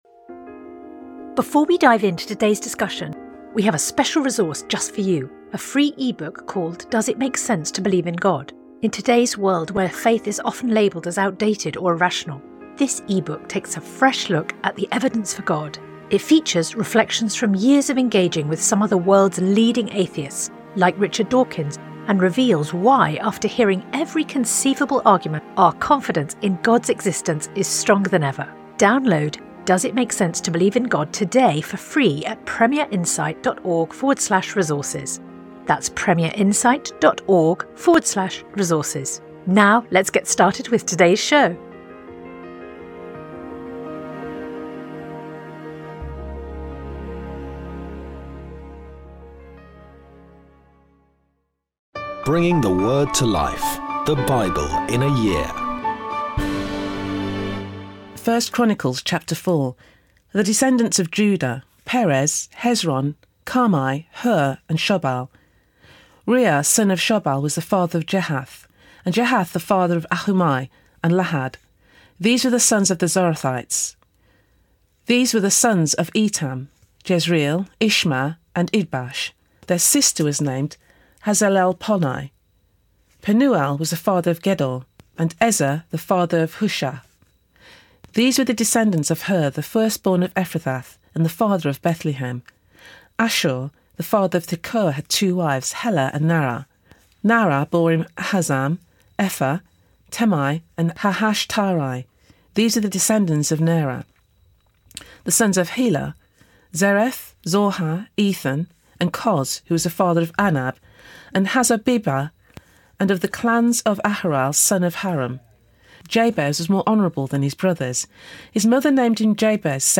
Today's readings come from 1 Chronicles 4; Luke 6